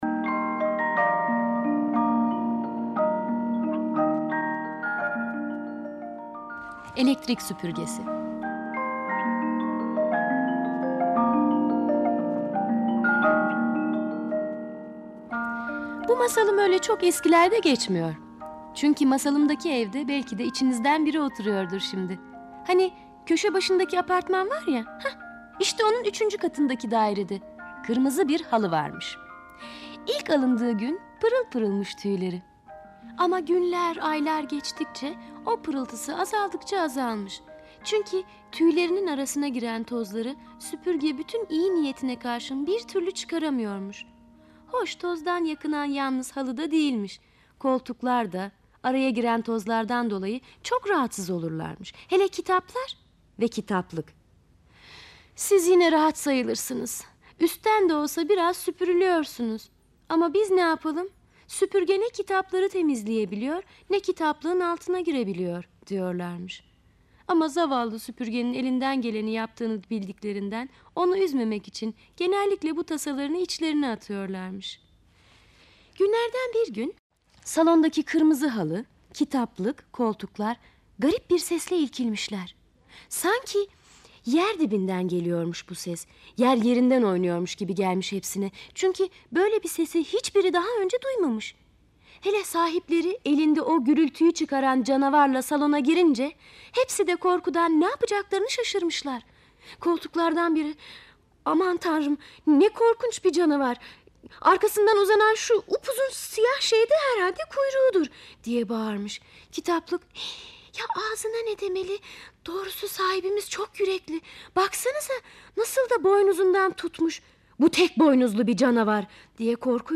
Elektrik süpürgesi sesli masalı, mp3 dinle indir
Sesli Çocuk Masalları